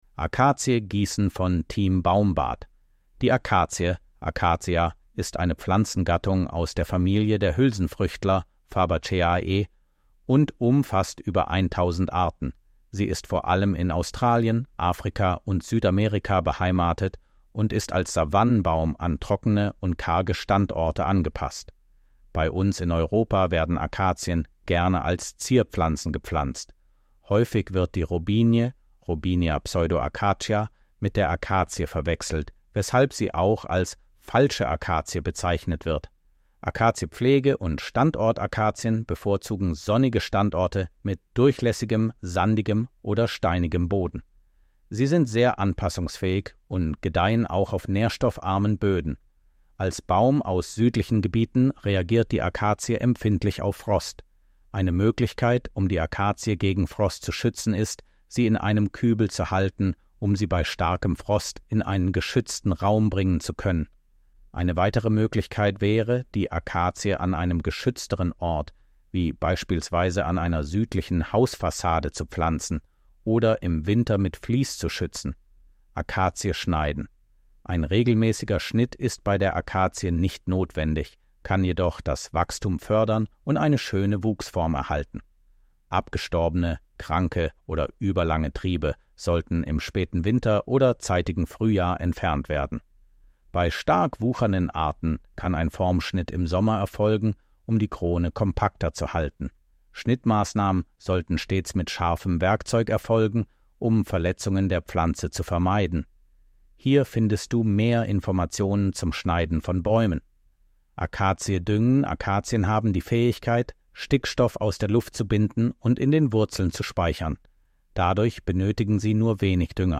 Artikel vorlesen